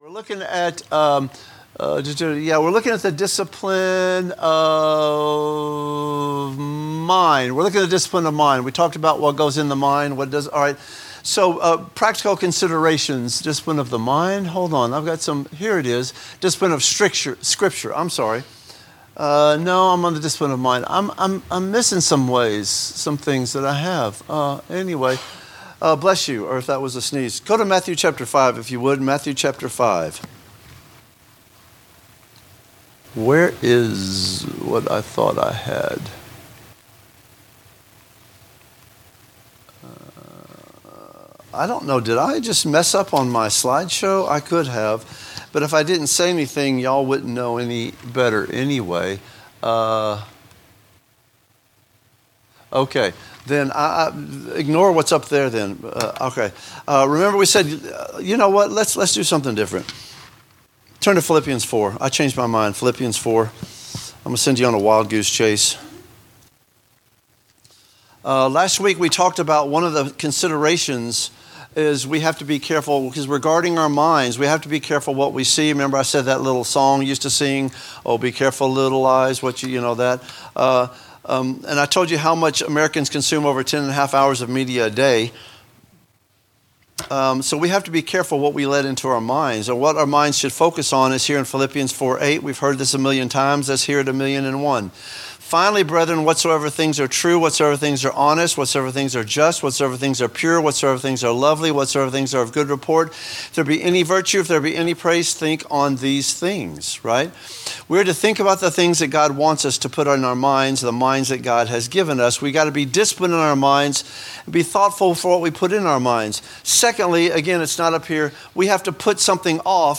A message from the series "Spiritual Disciplines."